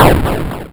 pit_trap_damage.wav